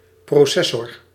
Ääntäminen
IPA: /prɔˈsɛ.sɔr/